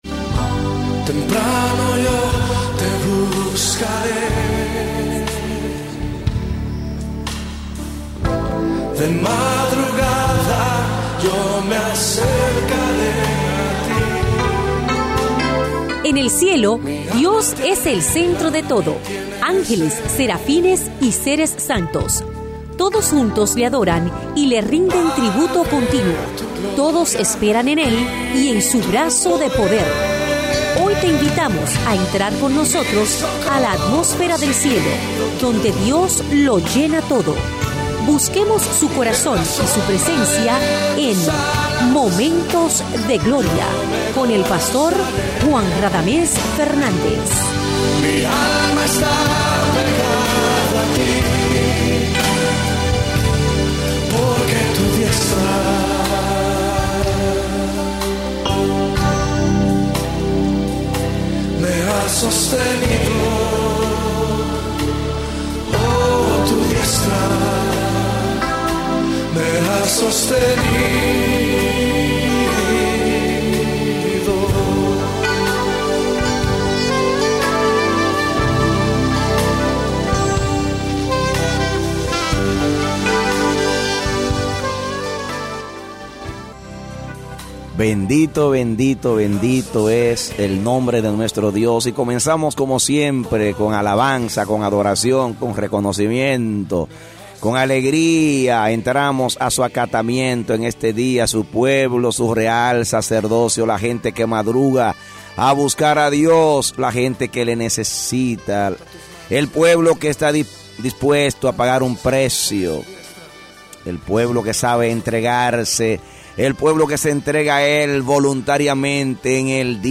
A mensaje from the serie "Programas Radiales."